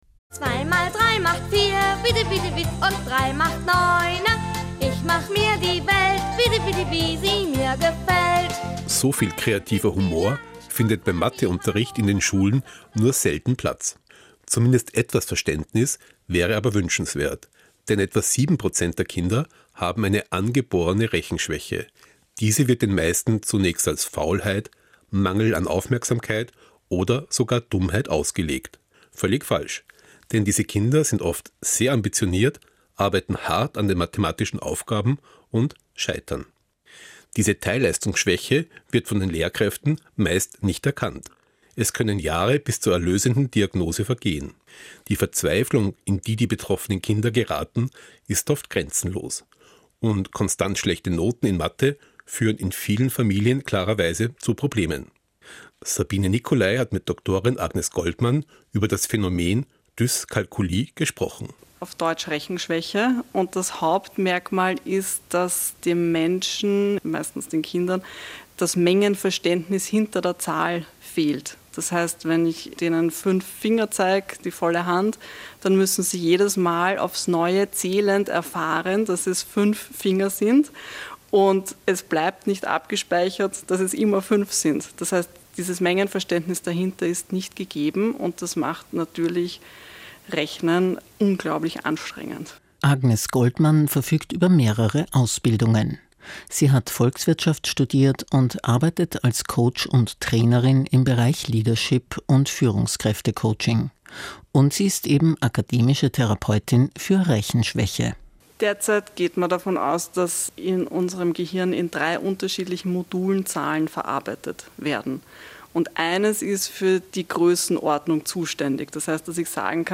Interview in der Reihe Radiodoktor